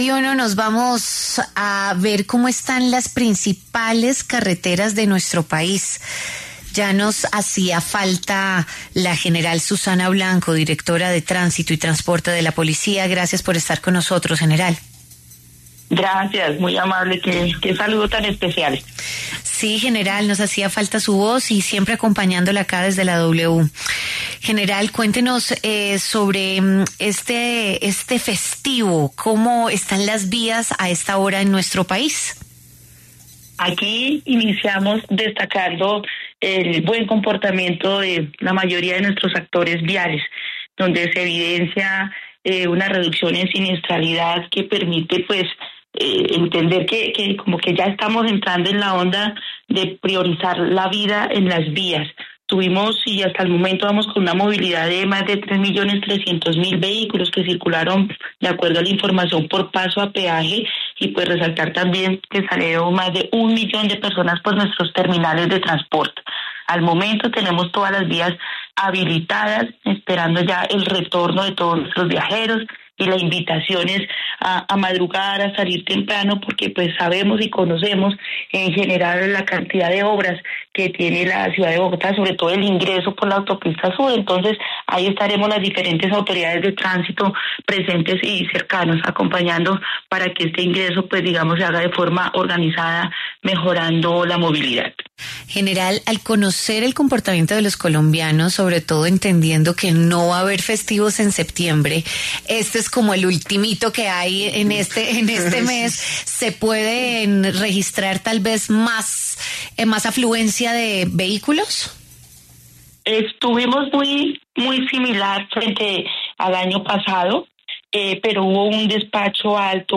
La general Susana Blanco hizo un balance en La W sobre el estado de las vías en este puente festivo.